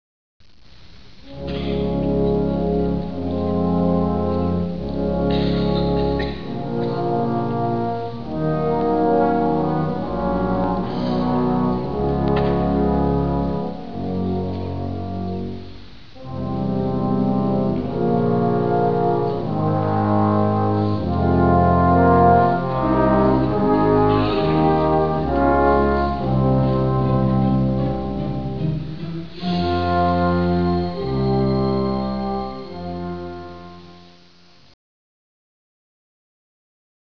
Trombone: Rouse, Mahler
Mettono in evidenza il suono del trombone come strumento solista e come parte della sezione degli ottoni.
Gustav Mahler (1860-1911), dalla Sinfonia n.2 in DO minore - "La Resurrezione" - La sezione dei tromboni della Filarmonica lavora insieme in una registrazione da "New York Philharmonic: The Mahler Broadcasts, 1948-1982" CD 2.